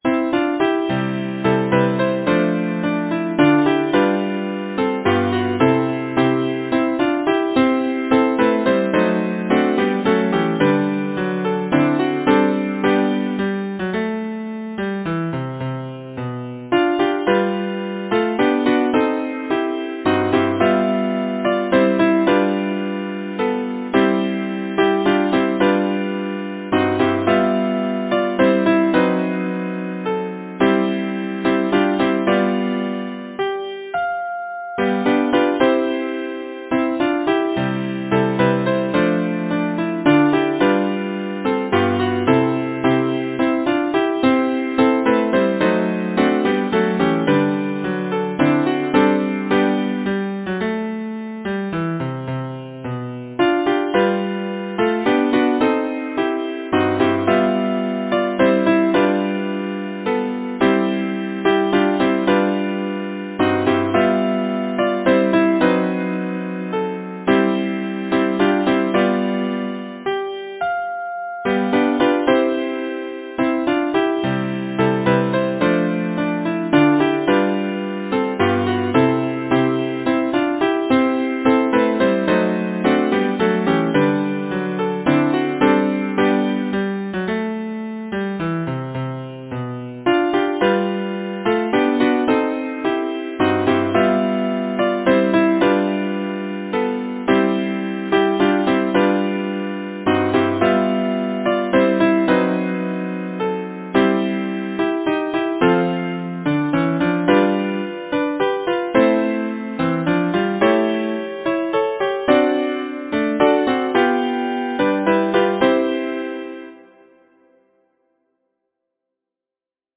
Title: Spring, all hail to thee! Composer: Robert Fairlamb Reah Lyricist: Thomas George Cox Number of voices: 4vv Voicing: SATB Genre: Secular, Partsong
Language: English Instruments: A cappella